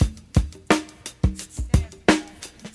• 87 Bpm High Quality Drum Beat B Key.wav
Free drum loop sample - kick tuned to the B note. Loudest frequency: 1518Hz
87-bpm-high-quality-drum-beat-b-key-Ssr.wav